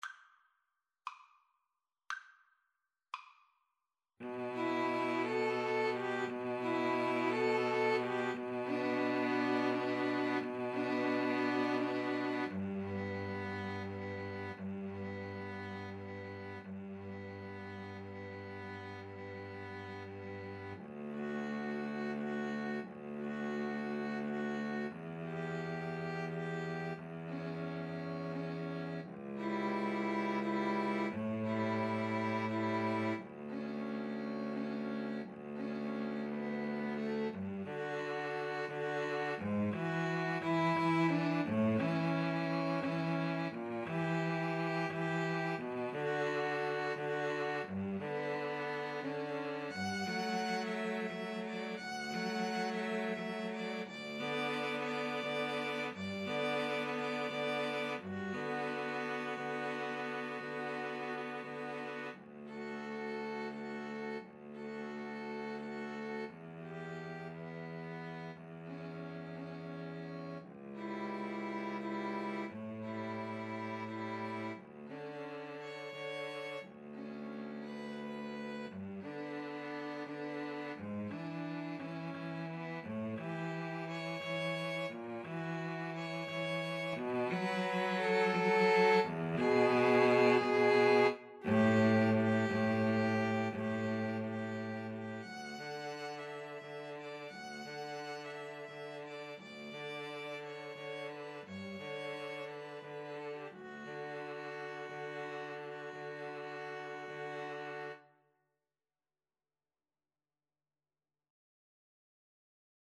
6/8 (View more 6/8 Music)
Classical (View more Classical 2-Violins-Cello Music)